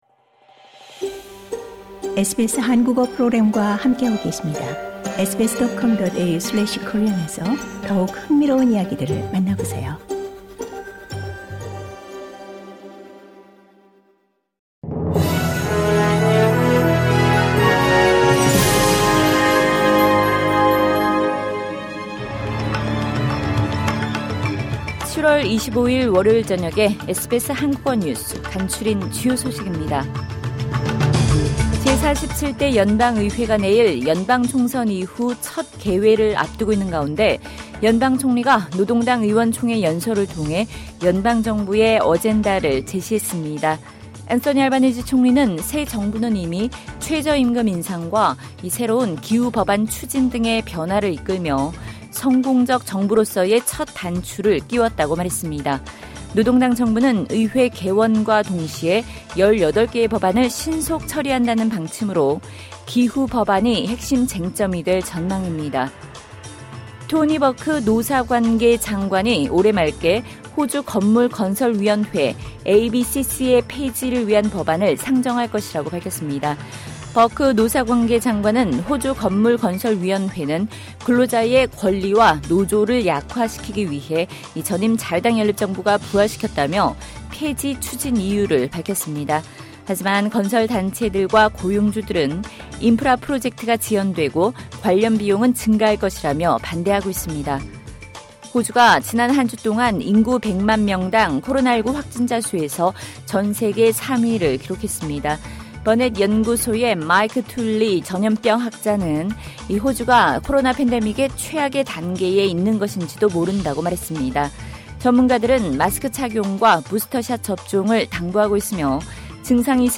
SBS 한국어 저녁 뉴스: 2022년 7월 25일 월요일
2022년 7월 25일 월요일 저녁 SBS 한국어 간추린 주요 뉴스입니다.